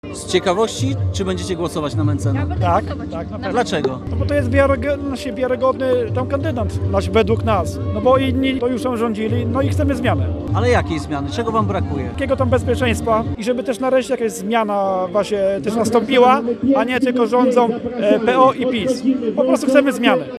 Sławomir Mentzen w środę na bytowskim rynku spotkał się z mieszkańcami miasta. Na wiecu pojawili się przede wszystkim młodzi i bardzo młodzi ludzie, którzy w wyborach jeszcze nie mogą brać udziału.
O nastroje i preferencje wyborcze zgromadzonych pytał